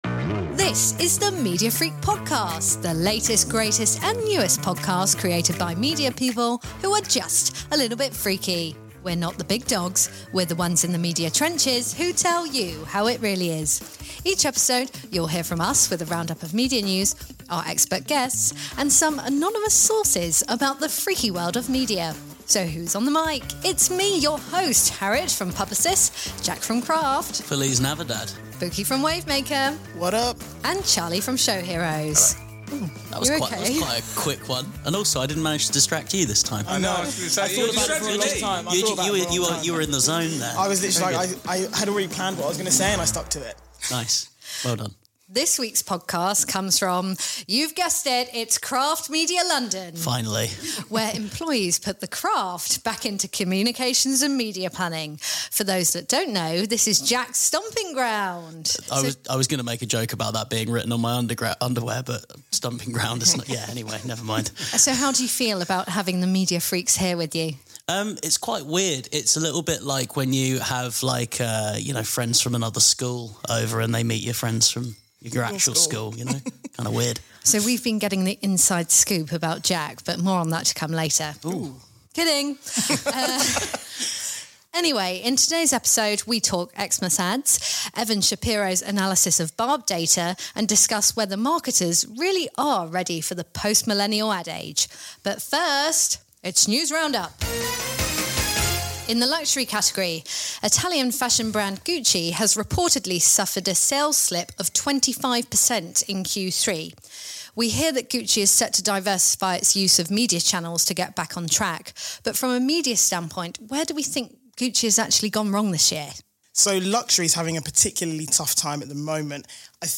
This is the marketing and advertising podcast presented by a team from across the industry. Each episode will feature news and discussion from across our business.